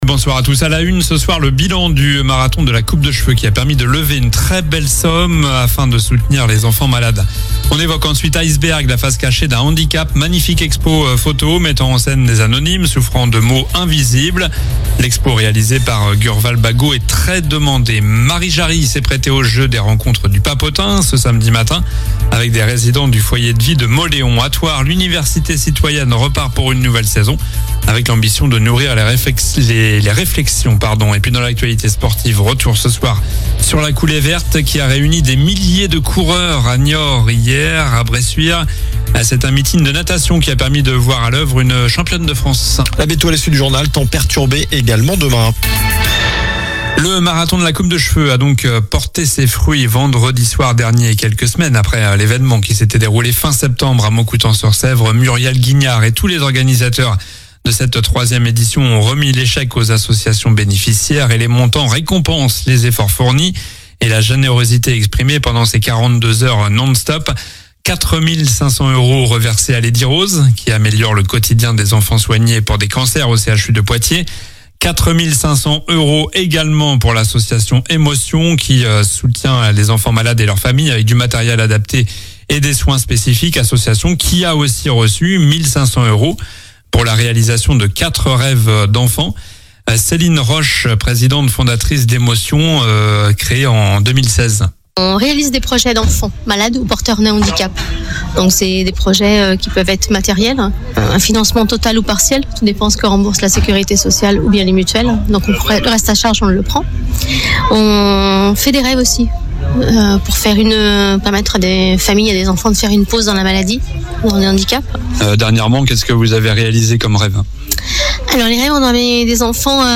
Journal du lundi 20 octobre (soir)